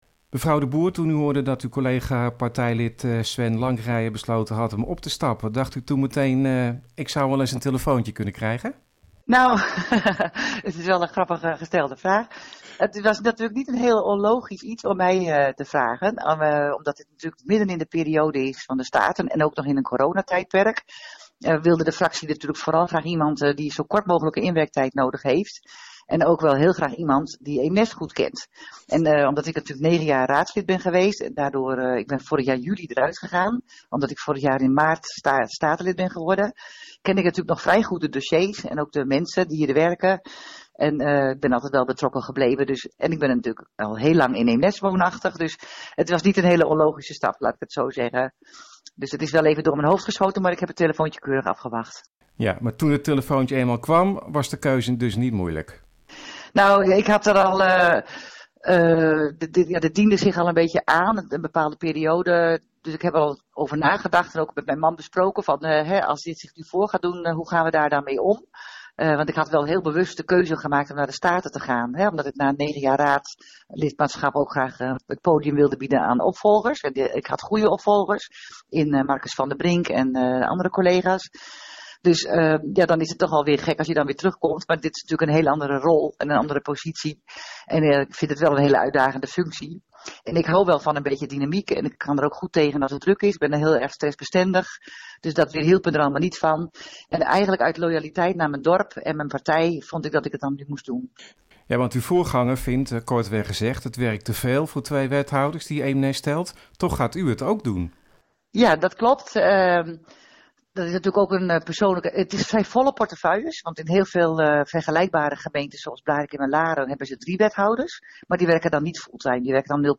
NH Gooi Zaterdag - Interview met nieuwe wethouder Eemnes Wilma de Boer
nh-gooi-zaterdag-interview-nieuwe-wethouder-eemnes-wilma-boer.mp3